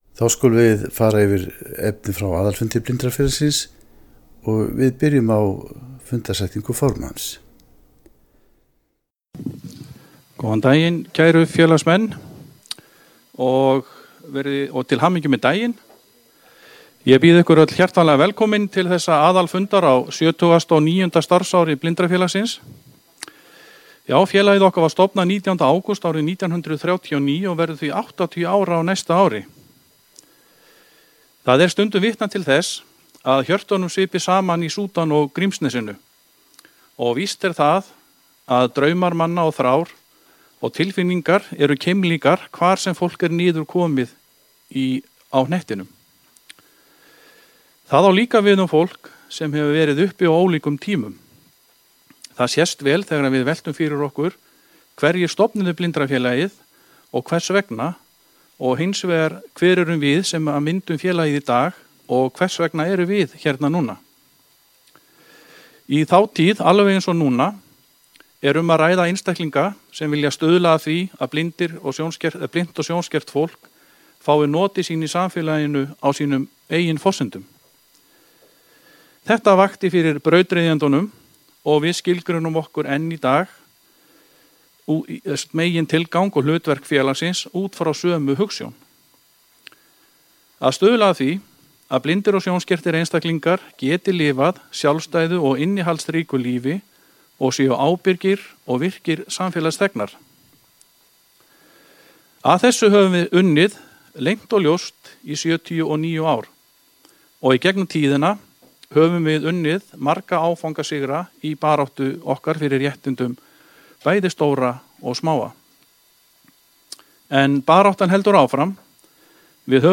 Hljóðritað hjá Hljóðbók slf. í Reykjavík, Stykkishólmi og í Quebec í Kanadaí maí 2018.
Efni frá aðalfundi Blindrafélagsins 12. maí sl.